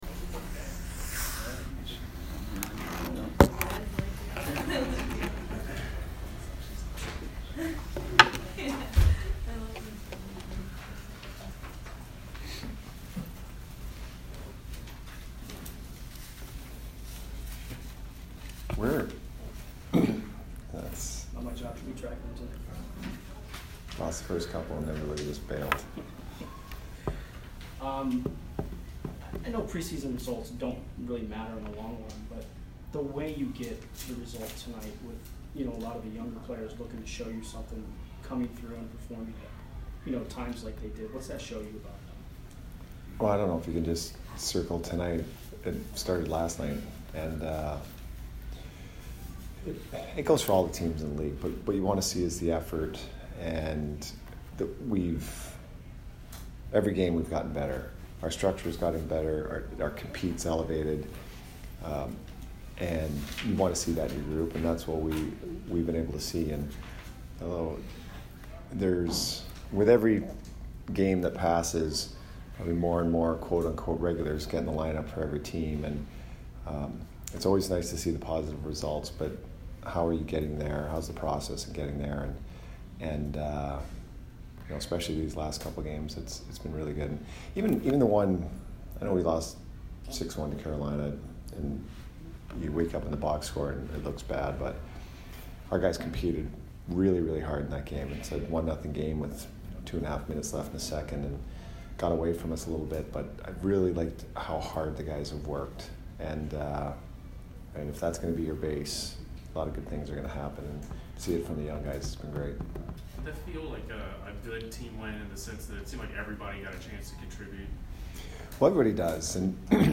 Jon Cooper post-game 9/22